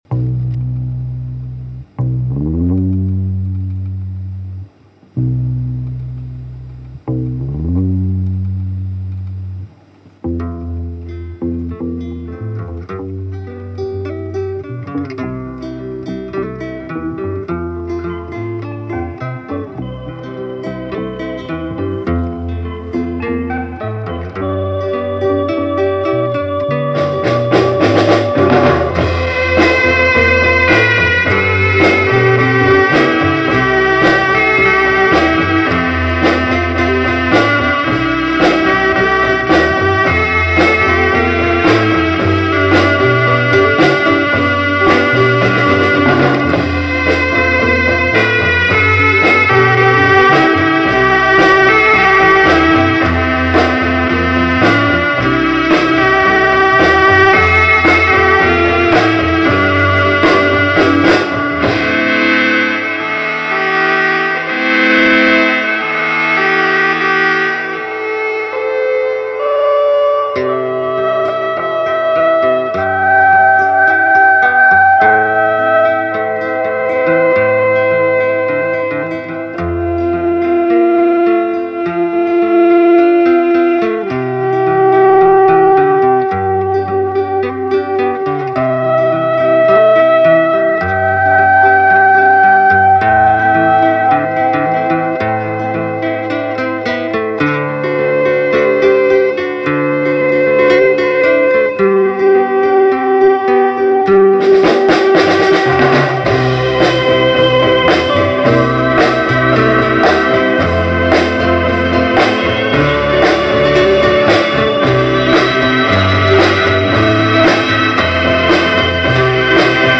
è il primo esperimento al mondo di opera-rock.